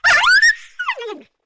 Index of /App/sound/monster/misterious_diseased_dog
fall_1.wav